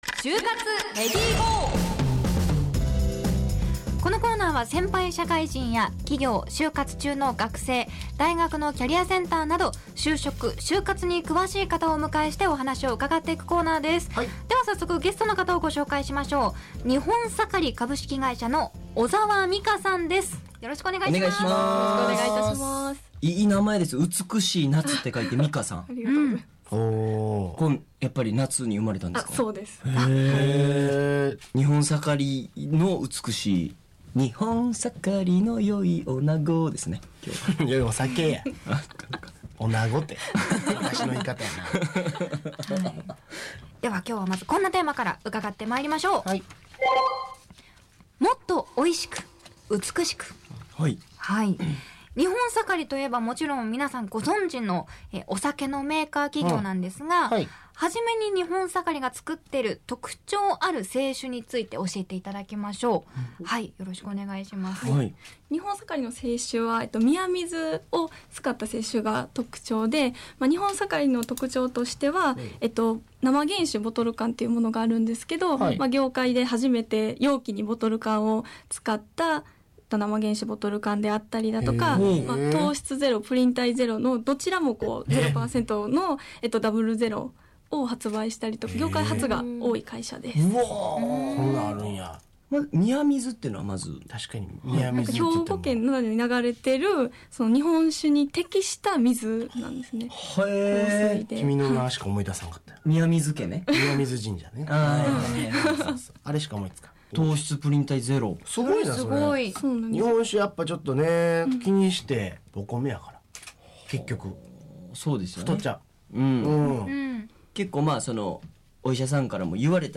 『ネイビーズアフロのレディGO！HYOGO』2020年10月2日放送回（「就活レディGO！」音声）